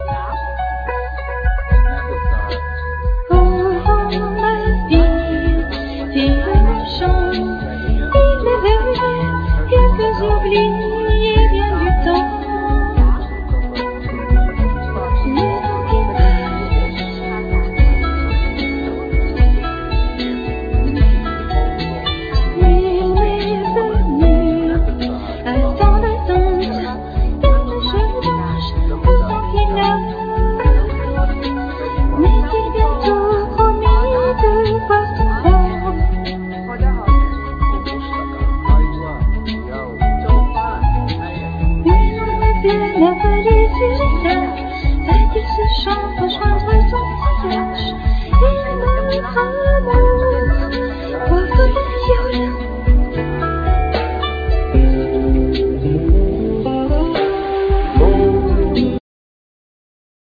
Piano,Keyboards,Programming
Guitar
Bass
Vocals